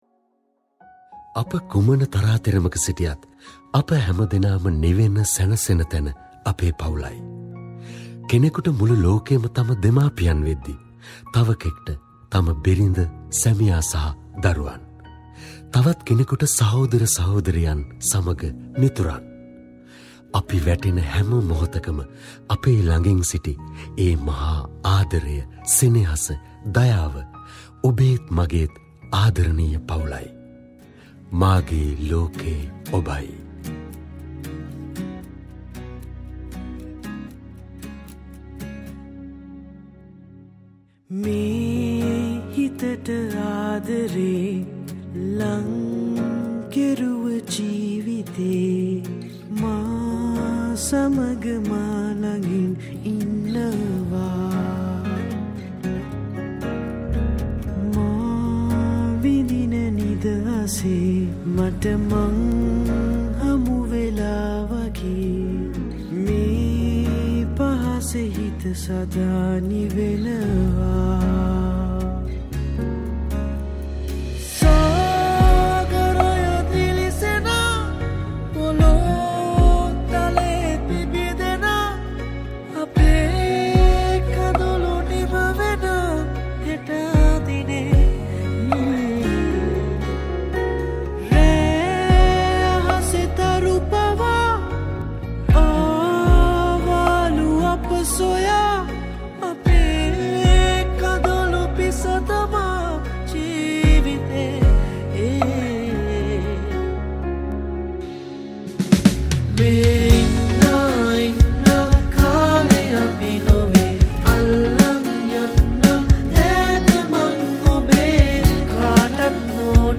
Percussion & Vocals
Guitar & Vocals
Keyboards & Vocals
Bass & Vocals
Drums & Vocals